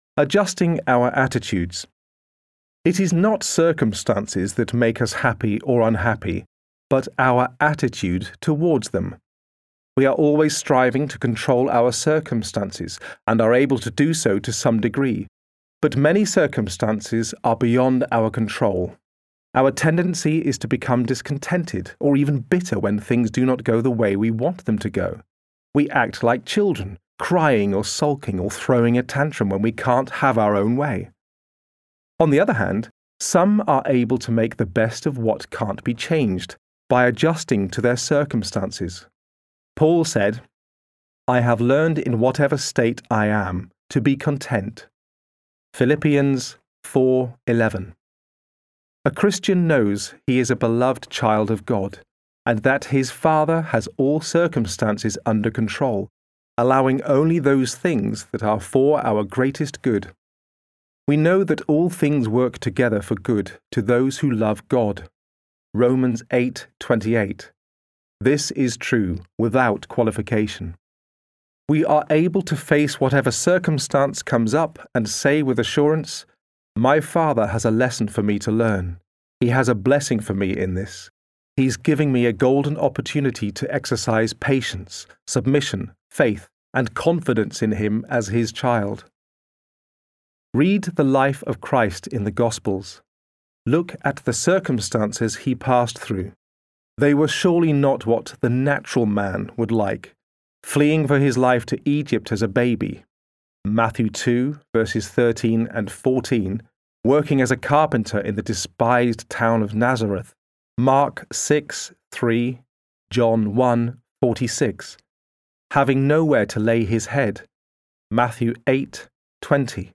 Audiobook Publications